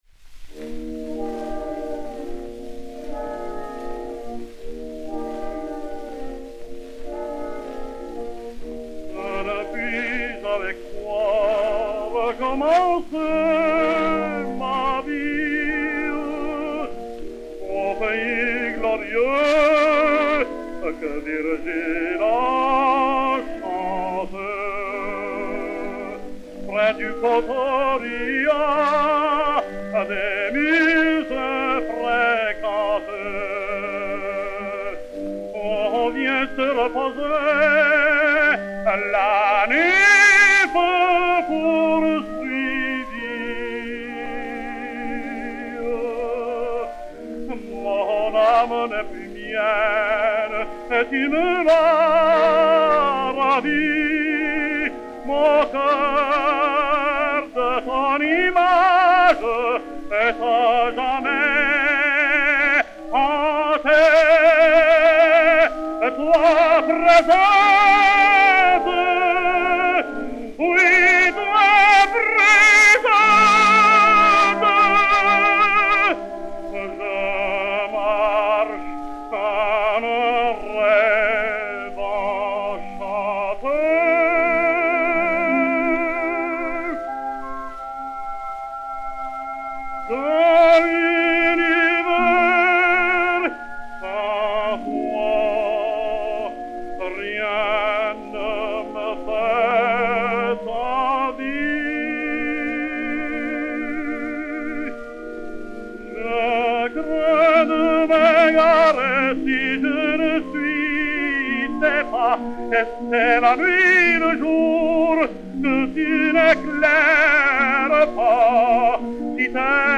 Enrico Caruso et Orchestre dir. Walter B. Rogers
B-14357, réédité sur Gramophone DA 107, mat. 7-32009, enr. à New York le 10 décembre 1914